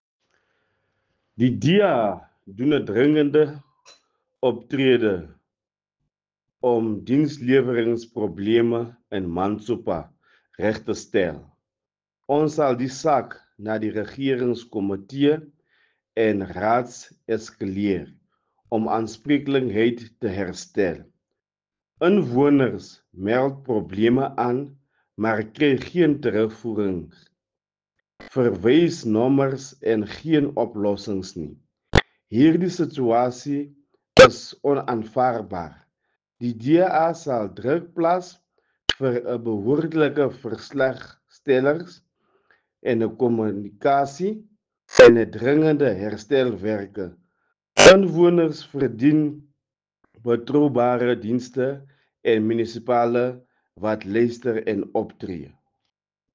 Sesotho soundbites by Cllr Nicky van Wyk.